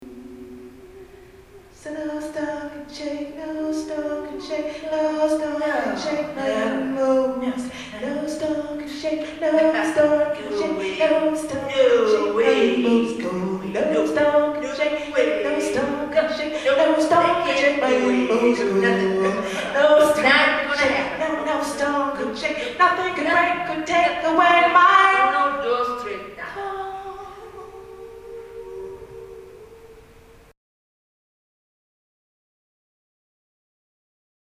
at the MCTC faculty recital.